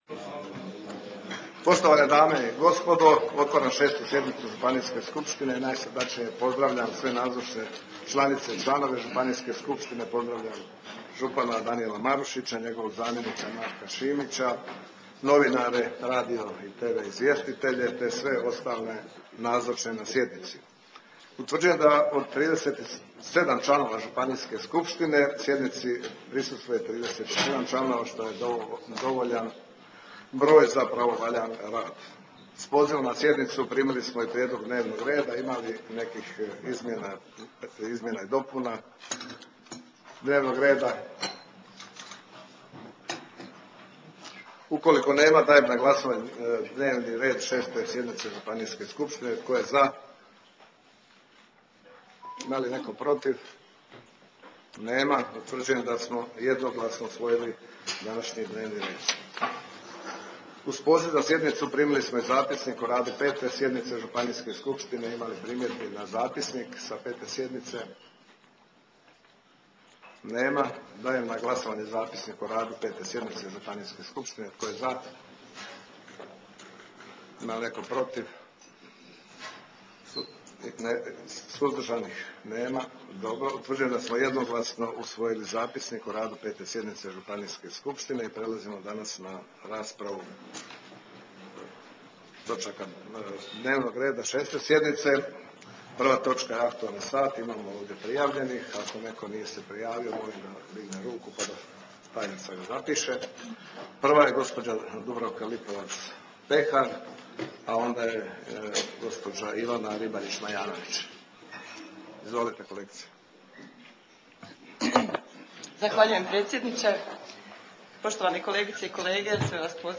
6. sjednica Županijske skupštine